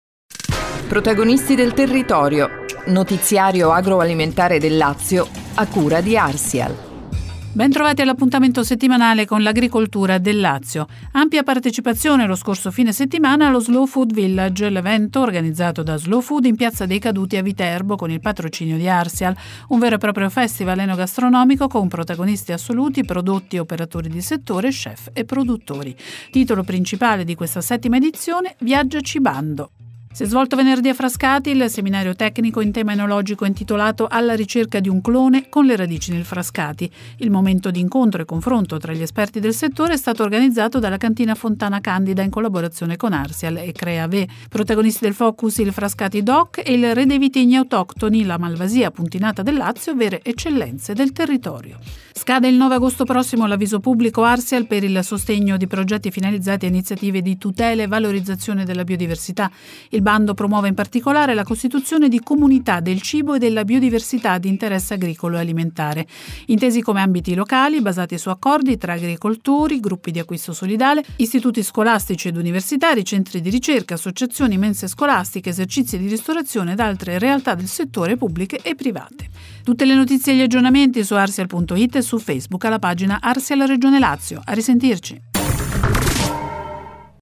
“Protagonisti del territorio”, il notiziario radiofonico Arsial